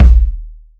KICK.97.NEPT.wav